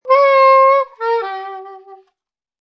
Descarga de Sonidos mp3 Gratis: saxofon 20.